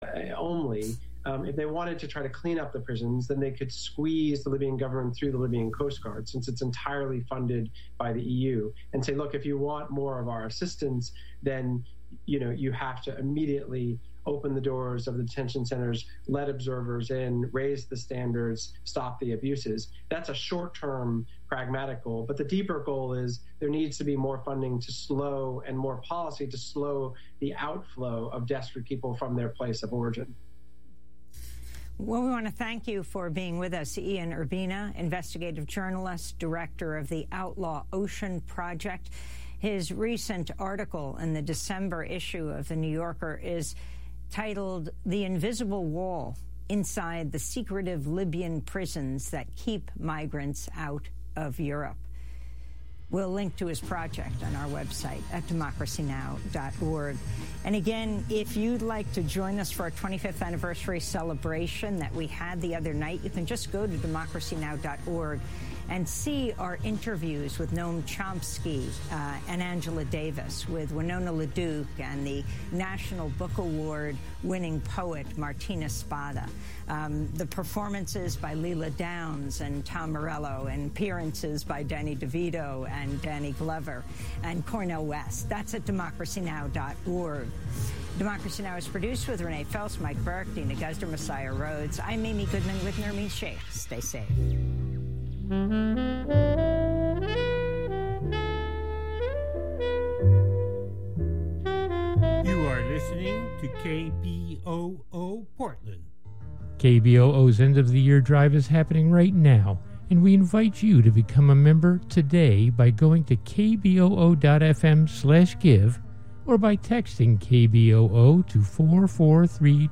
Evening News